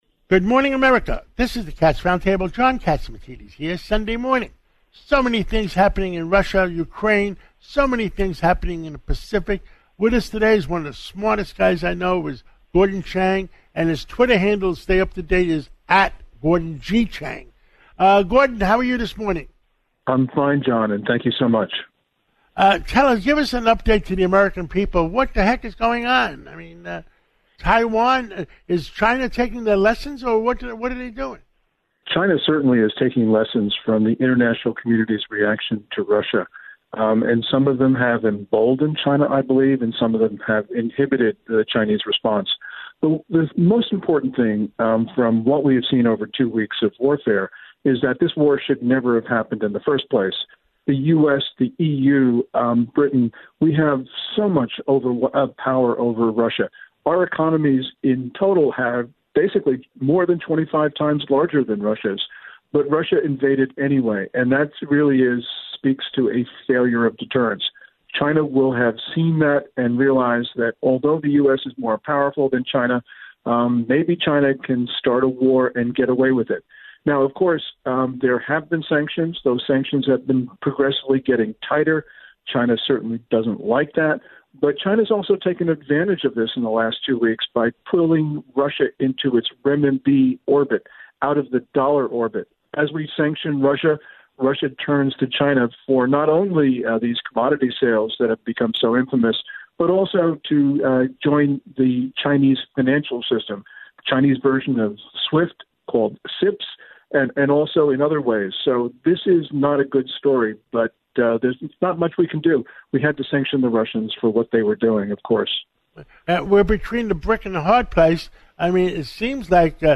The Cats Roundtable” radio show on WABC 770AM hosted by John Catsimatidis, Chang said that “this is really [about] two very large states – both aggressive, both militaristic. They see the world in the same terms. They identify the United States as their enemy. And they’ve been cooperating for a very long time.”